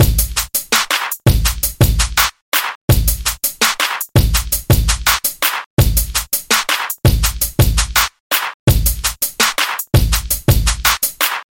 Tag: 83 bpm Rap Loops Drum Loops 1.95 MB wav Key : Unknown FL Studio